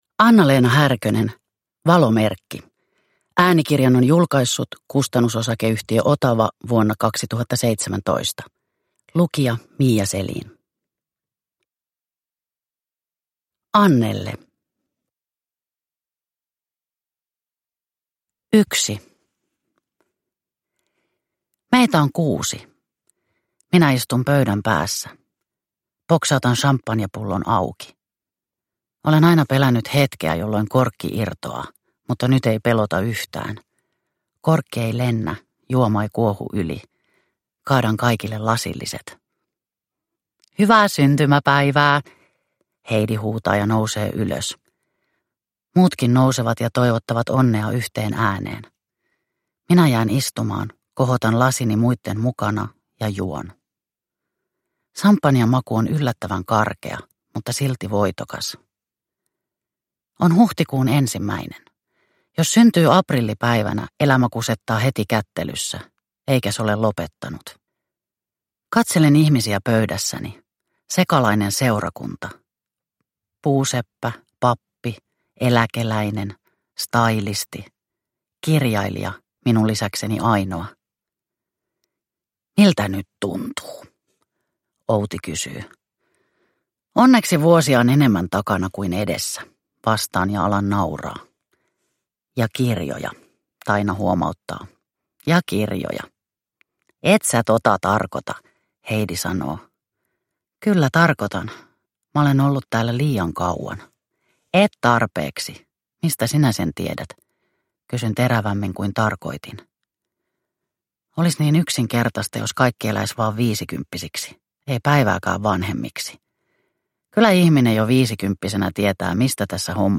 Valomerkki – Ljudbok – Laddas ner